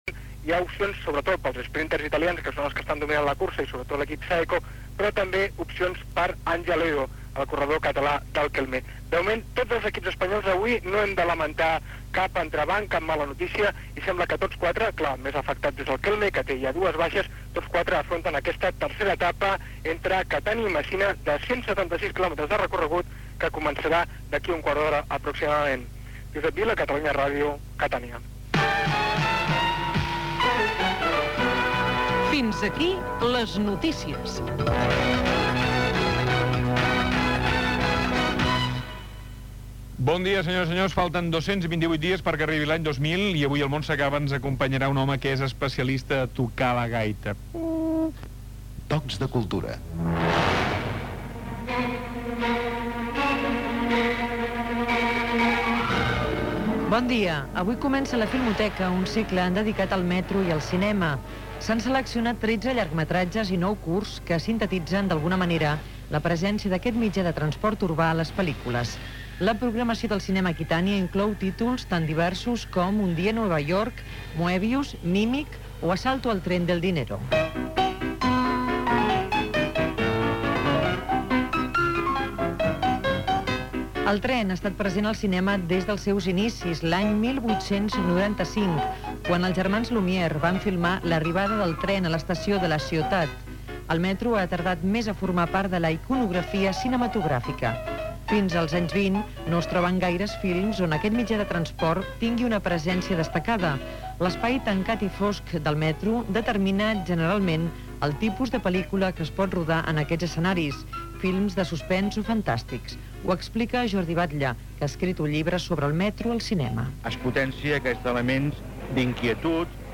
Final del butlletí de noticies (informació del Giro d'Italia) i principi de "El món s'acaba" amb la secció Tocs de Cultura: el tren al cinema, nova temporada del Palau de la Música. Indicatiu de l'emissora.
Entreteniment
FM